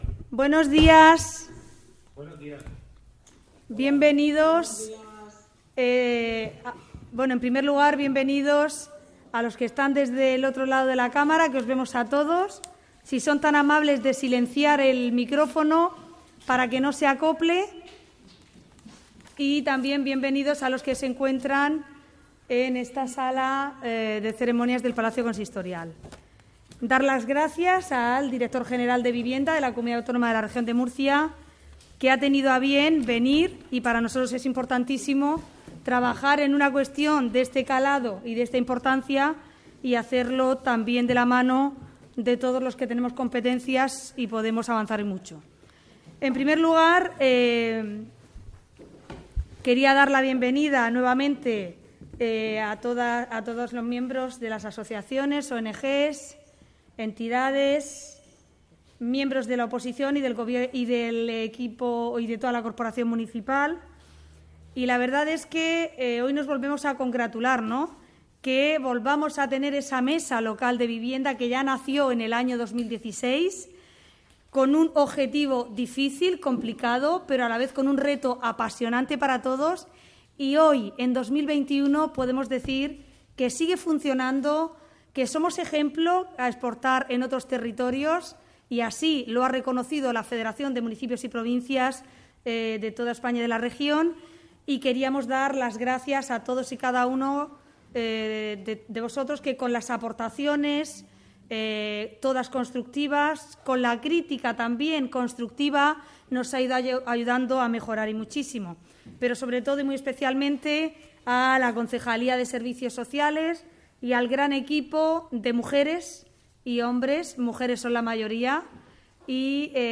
Audio: Declaraciones de Ana Bel�n Castej�n sobre la reuni�n de la Mesa de la Vivienda (MP3 - 12,64 MB)